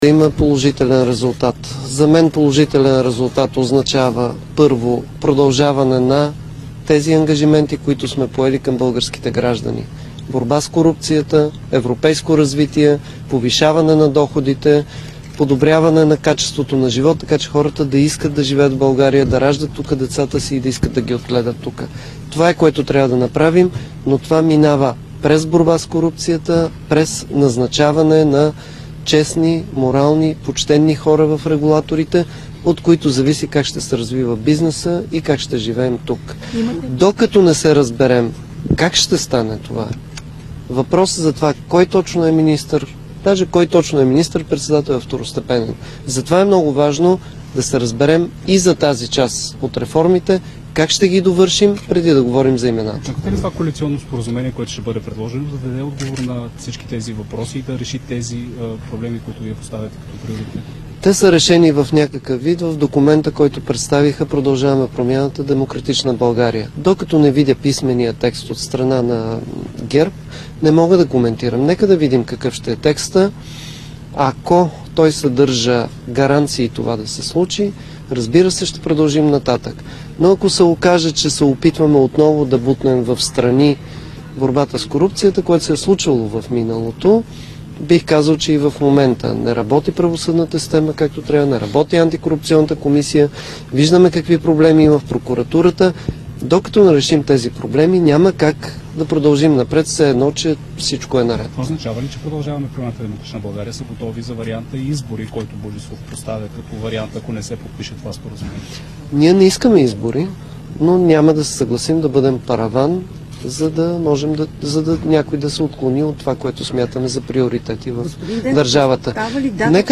9.45 - Брифинг на съпредседателя на ДПС Делян Пеевски за коалиционното споразумение и ротацията. - директно от мястото на събитието (Народното събрание)
Директно от мястото на събитието